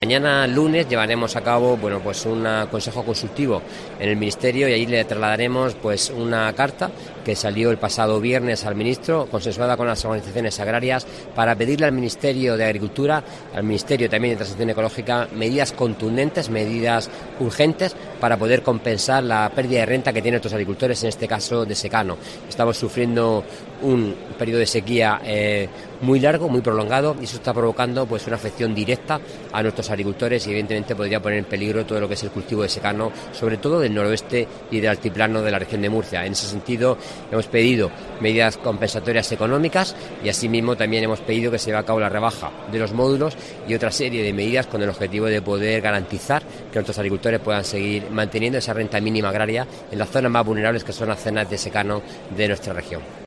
Antonio Luengo, consejero de Agua, Agricultura, Ganadería y Pesca